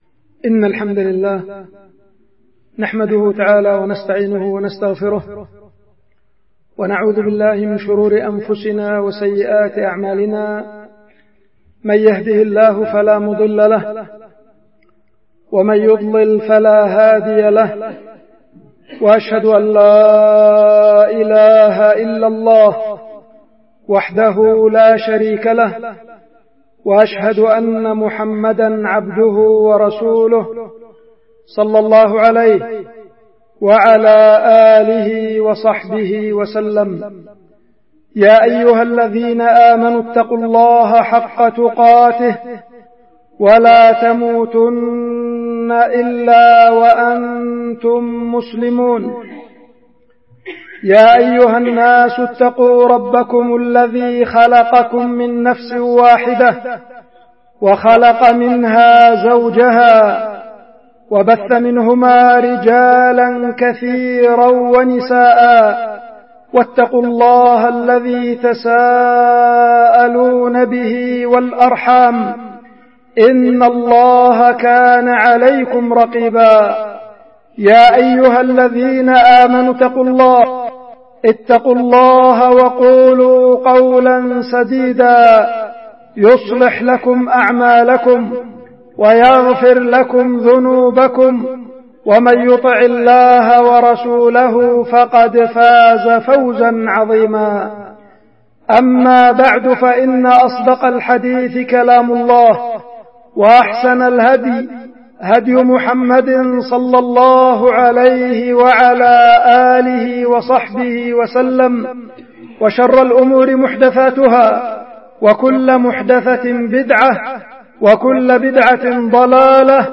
خطبة
القيت في الجامع الكبير بالعزلة- مديرية بعدان- إب-اليمن